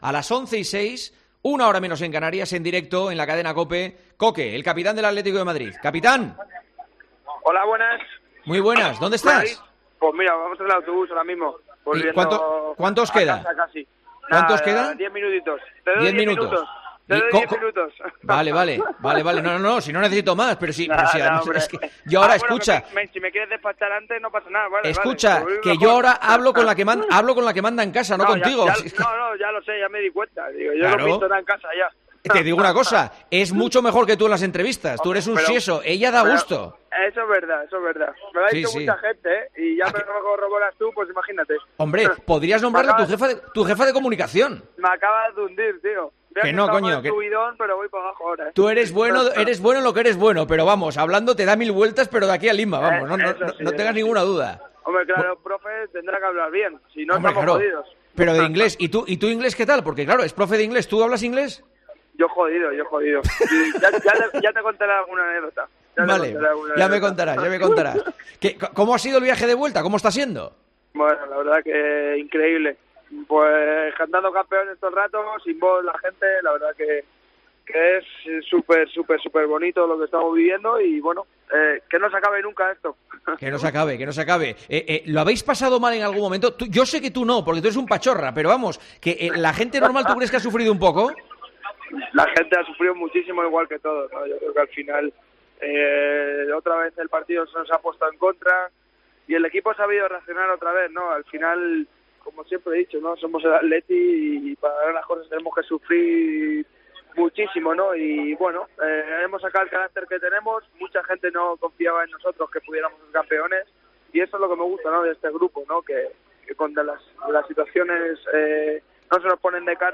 El Atlético de Madrid ha estado en el tramo final de Tiempo de Juego desde el autobús del equipo en su regreso a la capital tras conseguir el título de Liga.
"Estamos todos cantando el 'Campeones, Campeones'", ha descrito el capitán.